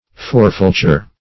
\For"fal*ture\